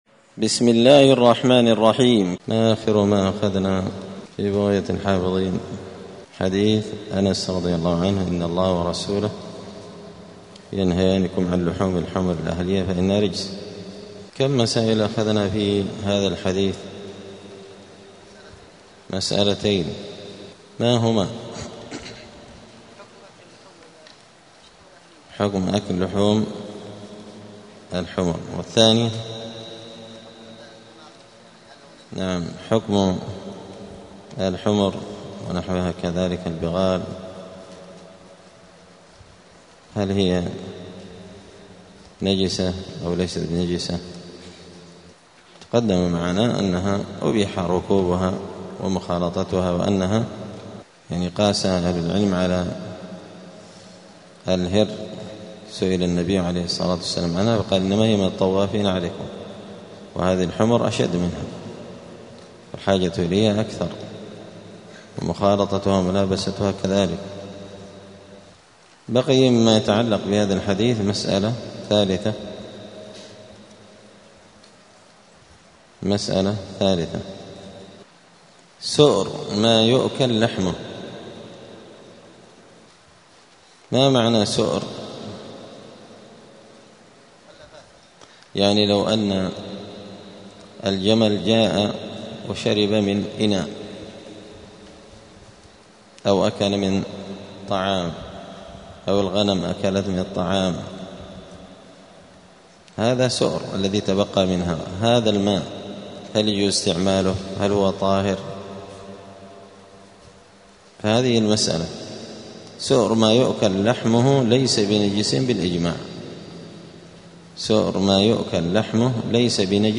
دار الحديث السلفية بمسجد الفرقان قشن المهرة اليمن
*الدرس التاسع عشر بعد المائة [119] {باب إزالة النجاسة حكم سؤر ما يؤكل لحمه}*